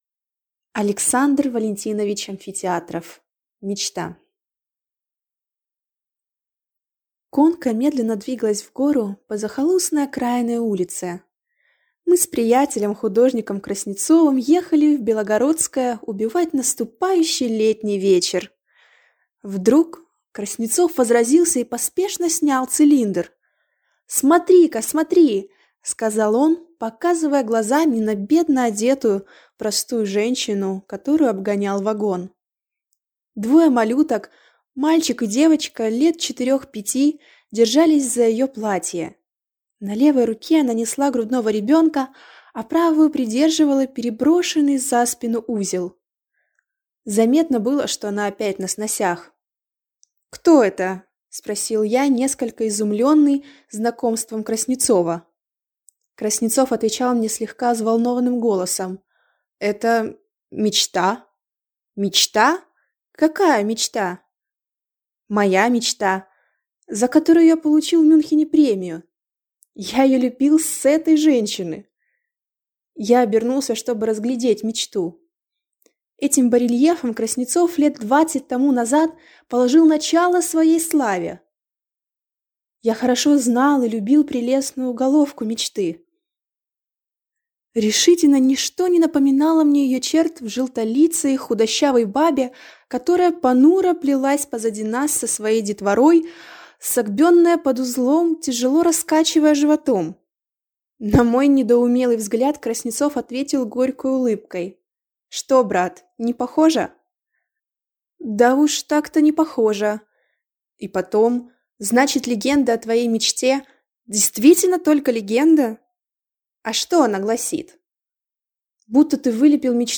Аудиокнига Мечта | Библиотека аудиокниг